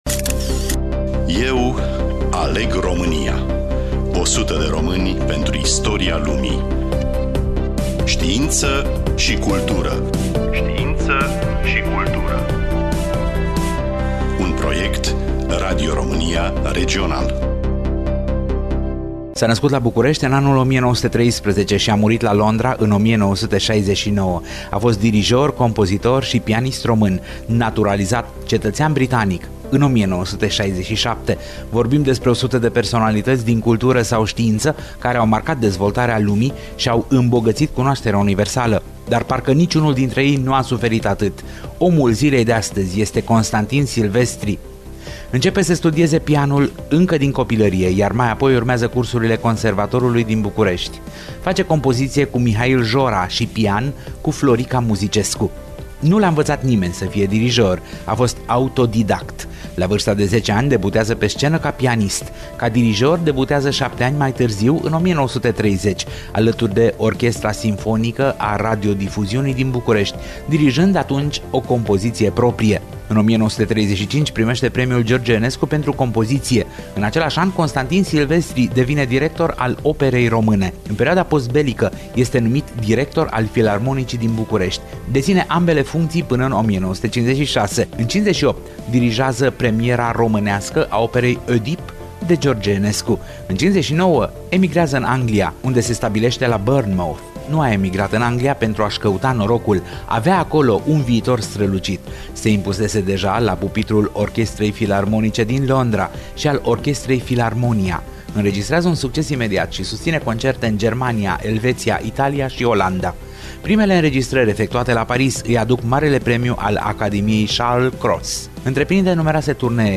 Radio Romania Regional vă prezintă astăzi, în cadrul proiectului Eu Aleg Romania, 100 de români pentru istoria lumii, povestea unuia dintre cei mai nedreptățiți oameni de cultură de la noi.